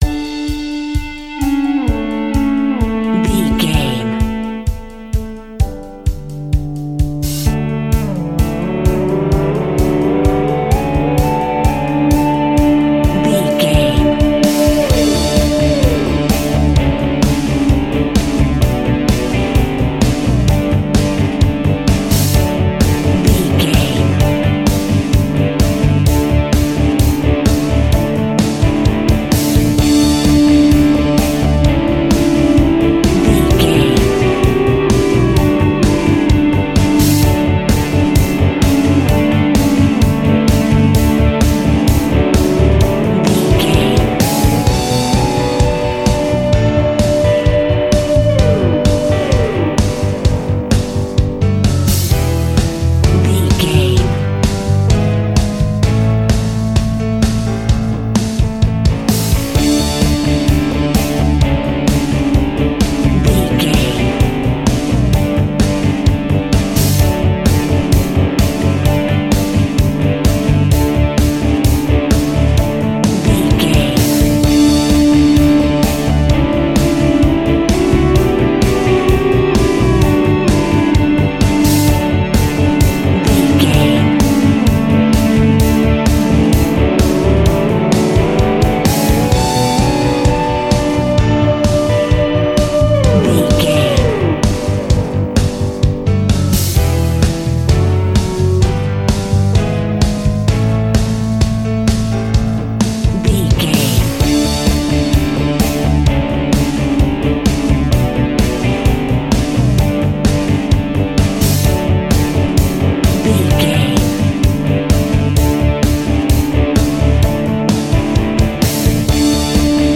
Ionian/Major
D
pop rock
energetic
uplifting
instrumentals
indie pop rock music
upbeat
groovy
guitars
bass
drums
piano
organ